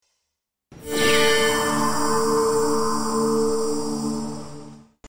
Звуки вспышки света
В коллекции представлены как резкие импульсные звуки, так и мягкие световые эффекты.
Классическая вспышка для монтажных работ